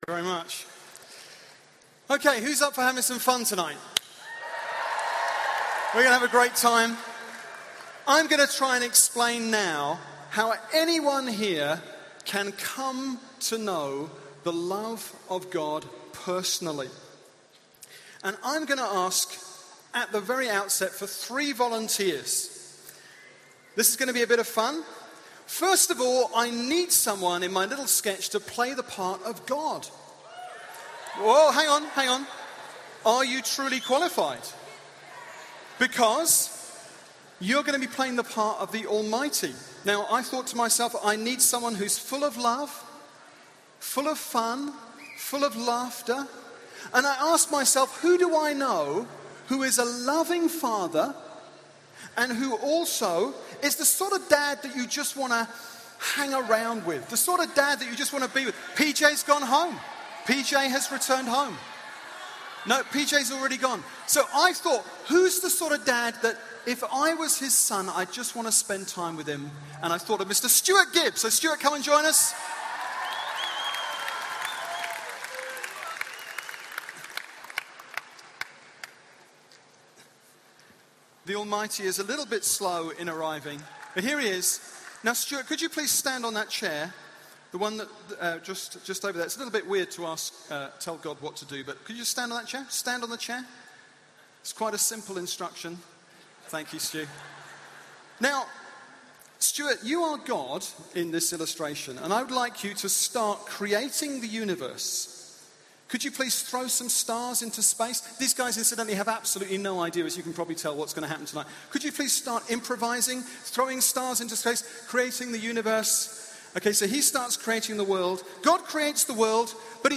This is a talk from Newday, Newfrontiers’ major youth event in 2008.